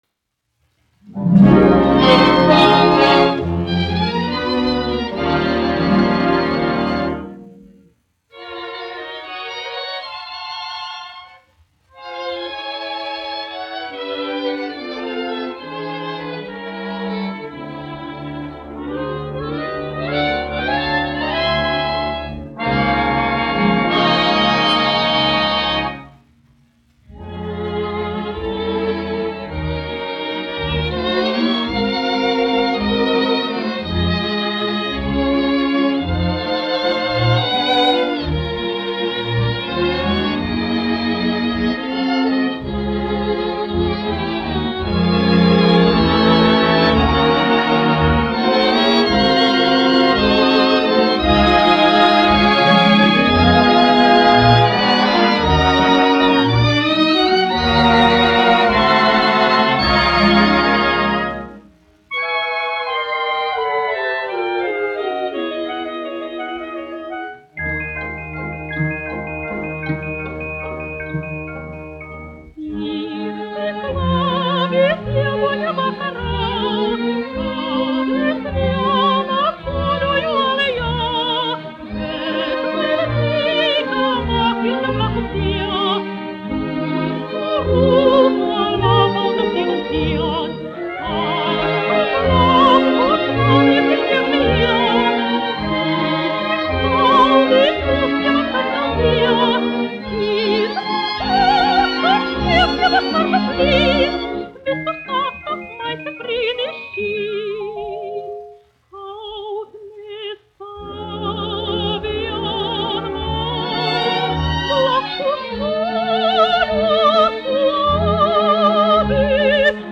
1 skpl. : analogs, 78 apgr/min, mono ; 25 cm
Dziesmas (augsta balss) ar orķestri
Populārā mūzika